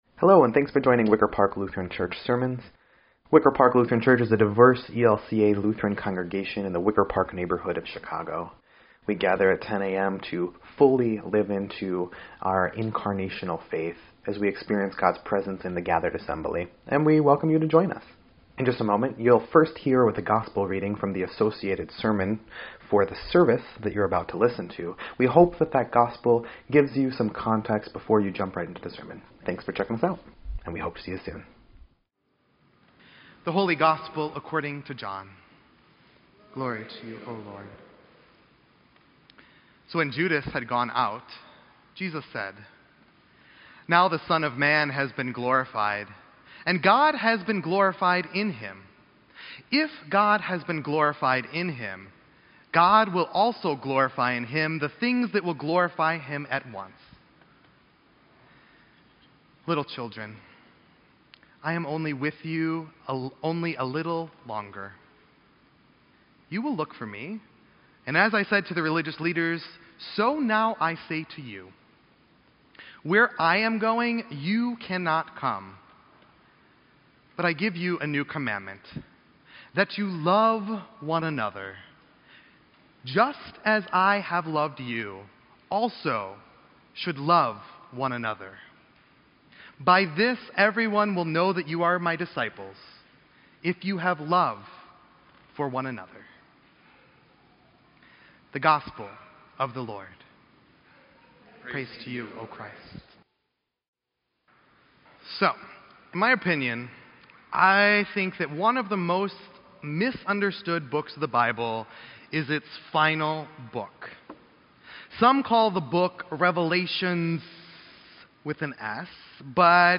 Fifth Sunday in Easter